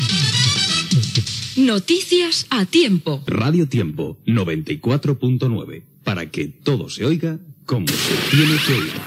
Careta de sortida i identificació de l'emissora